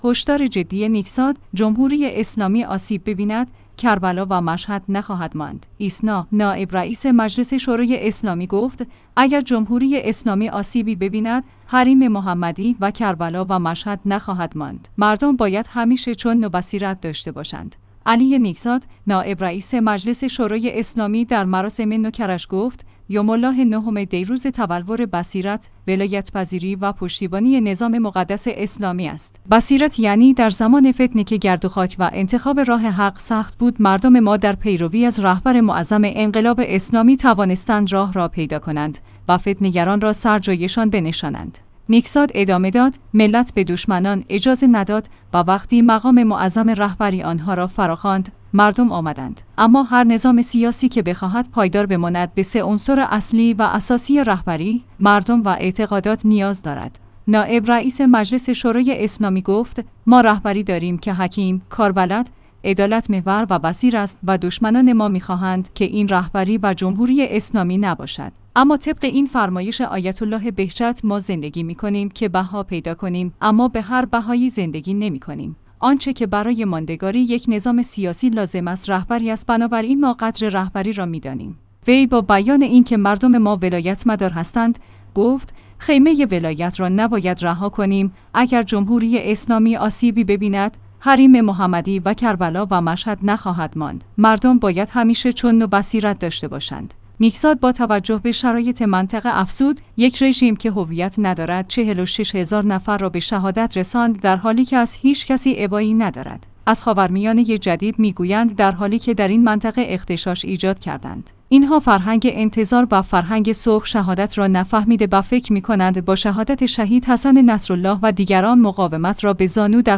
علی نیکزاد، نائب رئیس مجلس شورای اسلامی در مراسم ۹ دی کرج گفت: یوم الله نهم دی روز تبلور بصیرت، ولایت پذیری و پشتیبانی نظام مقدس اسلامی است.